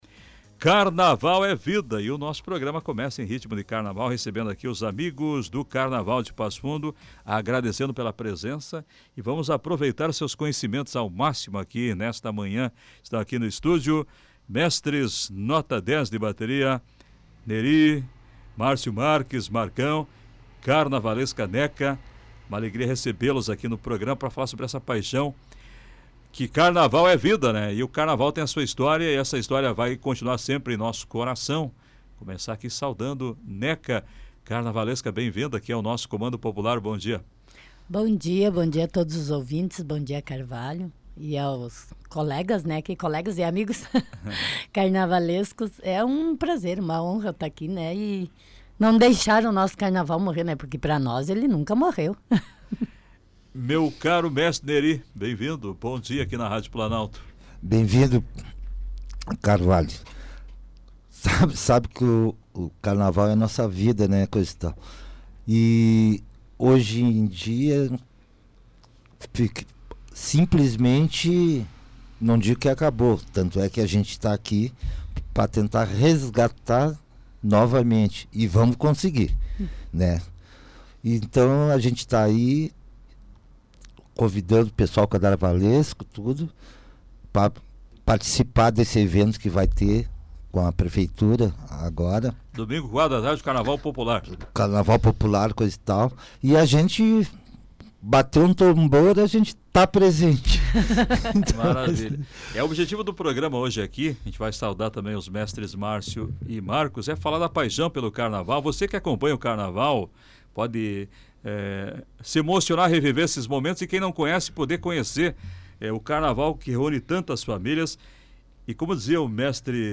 Com programas, quadros e entrevistas, em todos os desfiles e eventos, está sempre presente.